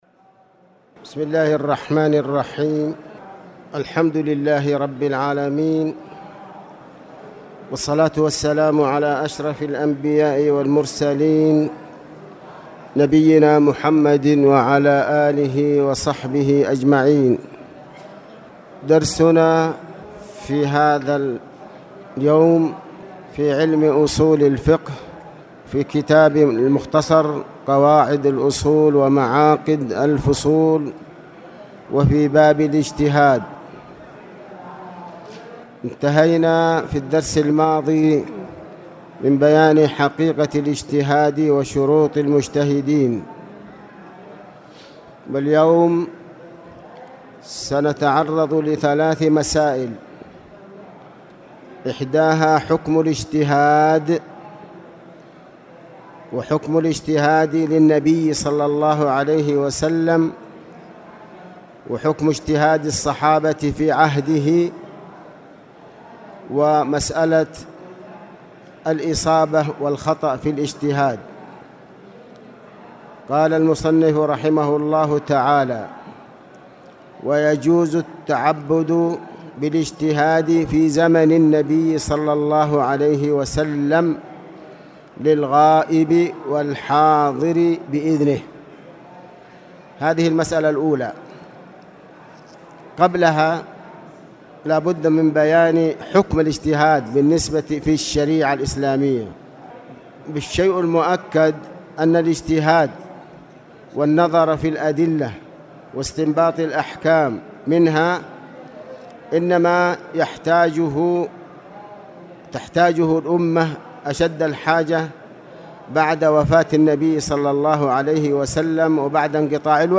تاريخ النشر ٢١ شعبان ١٤٣٨ هـ المكان: المسجد الحرام الشيخ